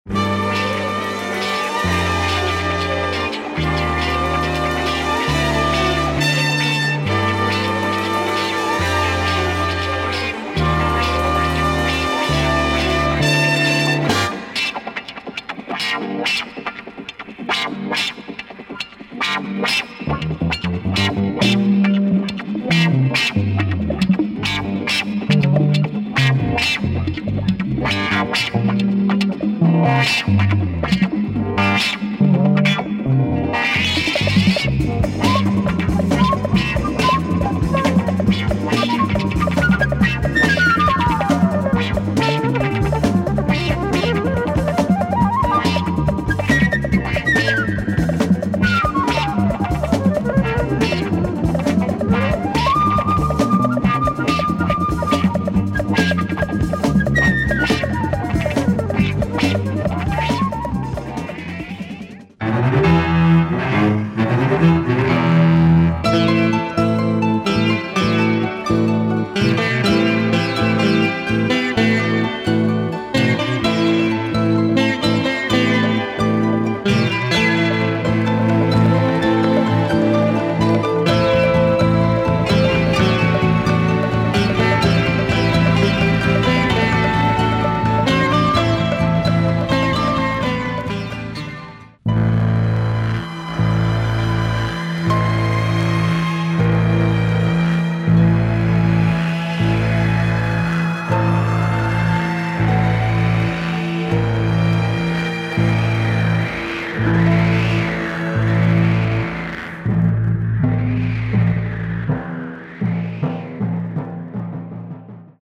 Obscure French Canadian album.
is a nice groove with flute, funky drums and wah wah
has the sitar OST sound
sounds as spooky as a library music album